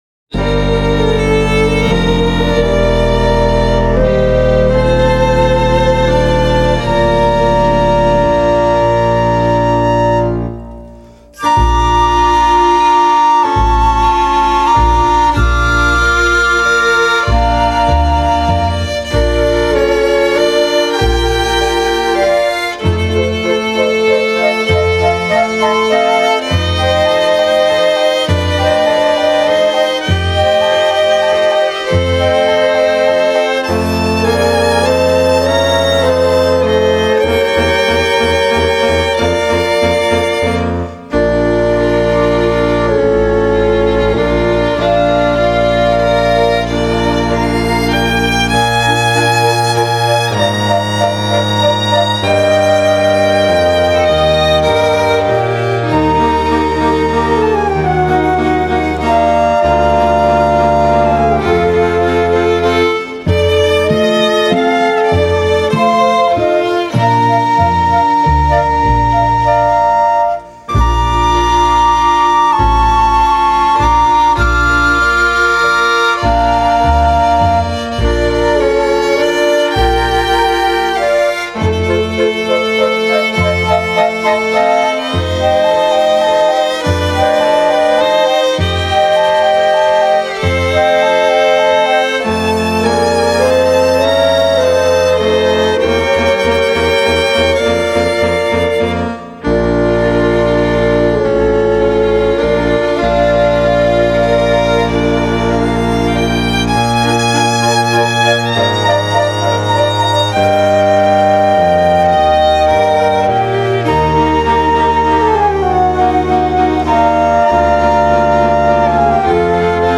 815   10:02:00   Faixa:     Valsa